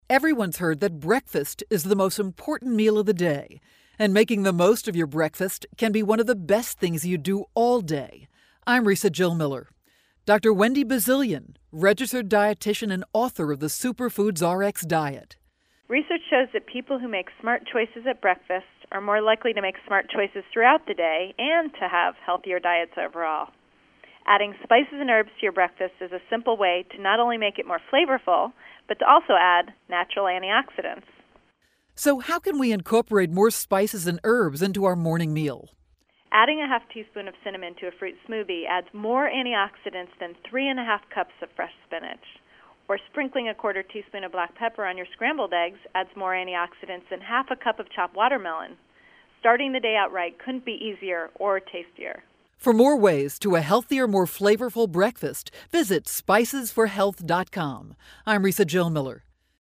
February 7, 2012Posted in: Audio News Release